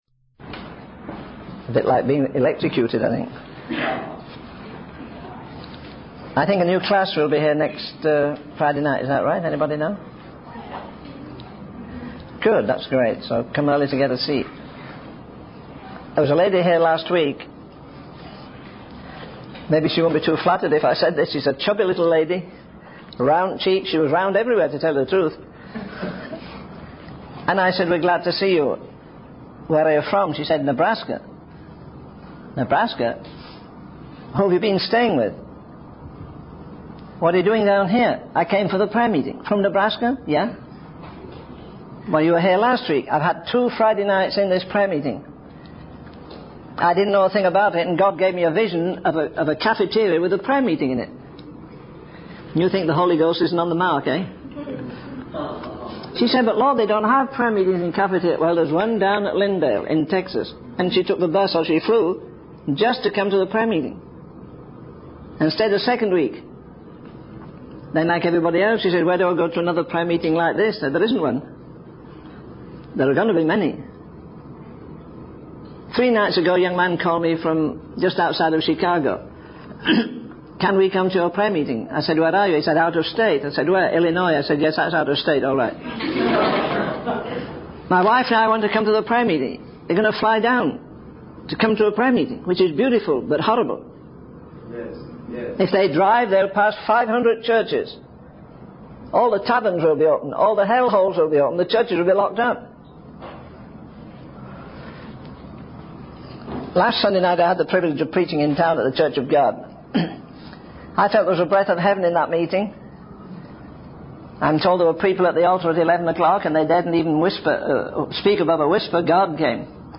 In this sermon, the speaker emphasizes the importance of having unwavering faith in God, even in the midst of challenging circumstances. He uses the example of a man who remained calm and unshaken during a violent storm at sea, demonstrating that having nothing can actually be an advantage because there is nothing to lose.